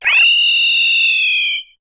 Cri d'Étouraptor dans Pokémon HOME.